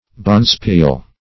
Search Result for " bonspiel" : The Collaborative International Dictionary of English v.0.48: Bonspiel \Bon"spiel\ (b[o^]n"sp[=e]l), n. [Scot.; of uncertain origin.] A curling match between clubs.